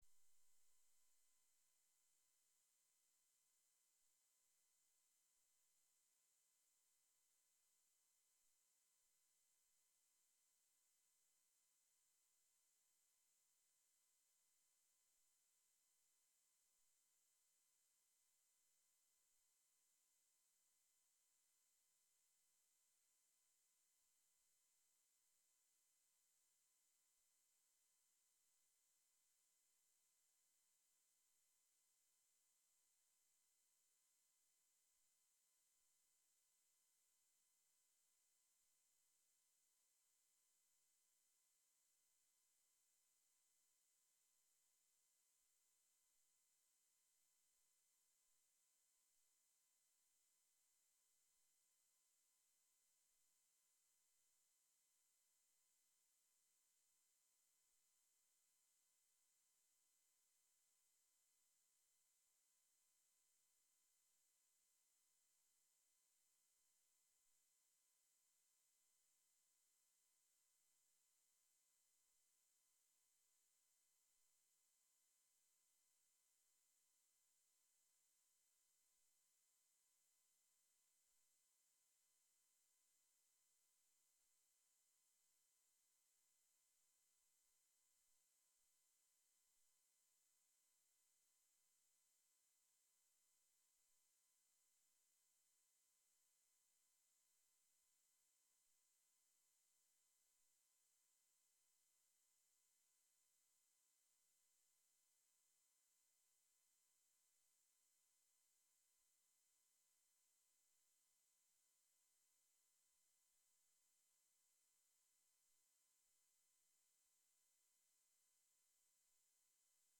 Live from New Ear Inc: New Ear Inc (Audio)